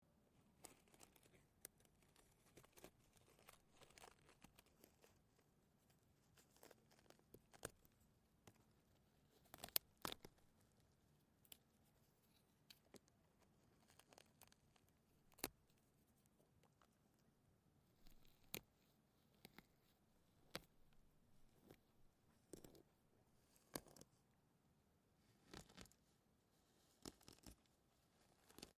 Pulling roots of branches from ground
Duration- 28 s Environment - Light breeze, mountains, open air, waterfall, rivers, and passing by cars. Description - Pulling, branches, breaking, tearing out of ground. stretching, friction, hand rubbing, up rooting from ground.